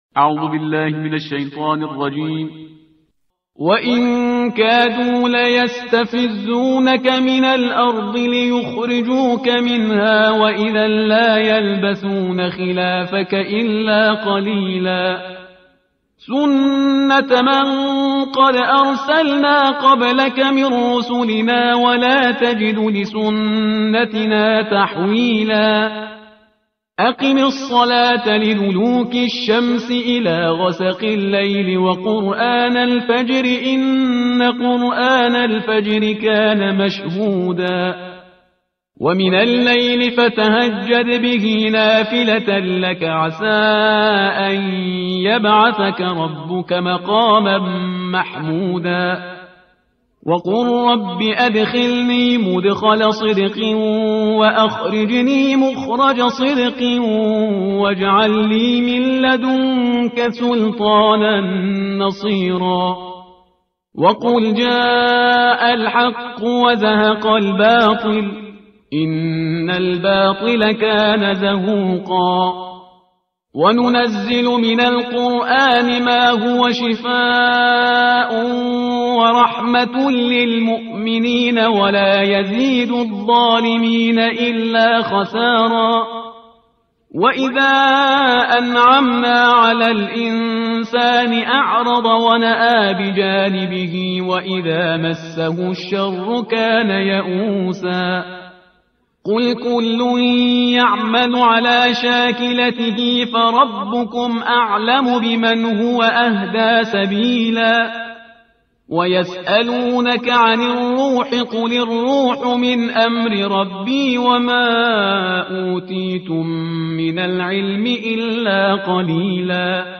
ترتیل صفحه 290 قرآن با صدای شهریار پرهیزگار